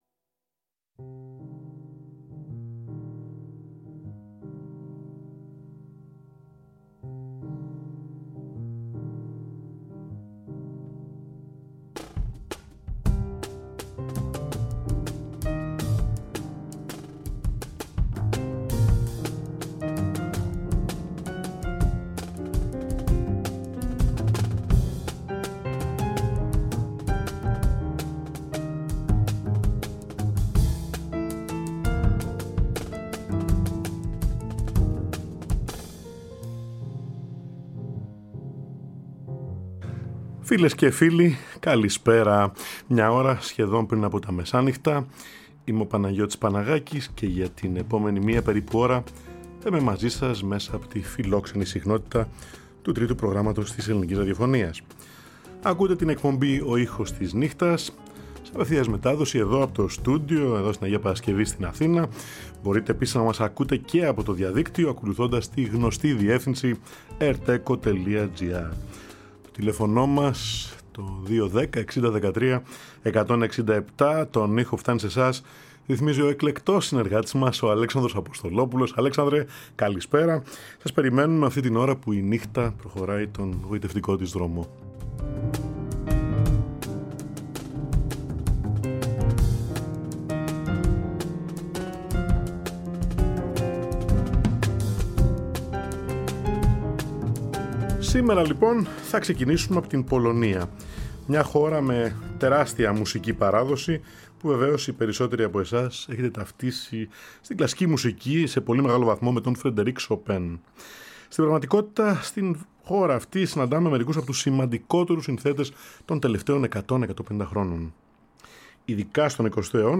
Σοβιετικός λυρισμός για τσέλο και πιάνο
πιανίστας
σοπράνο